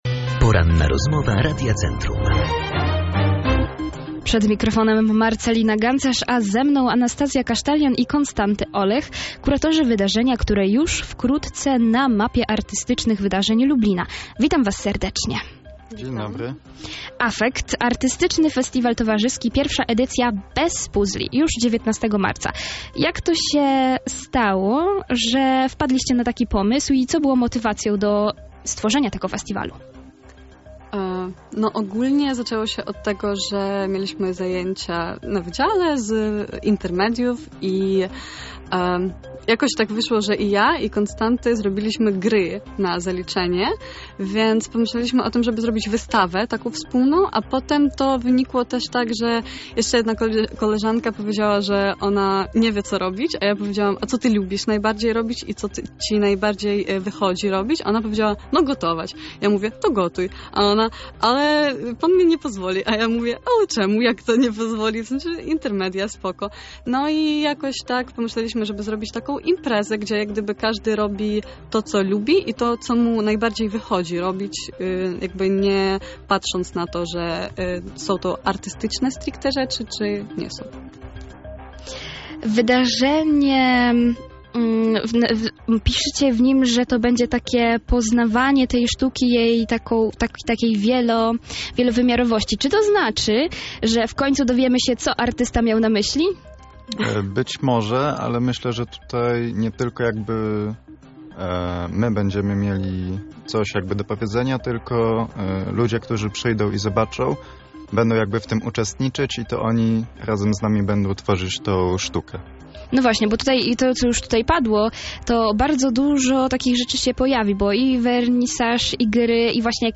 – To na początku miała być tylko wystawa – zdradzili podczas Porannej Rozmowy Radia Centrum.
Artystyczny Festiwal Towarzyski – cała rozmowa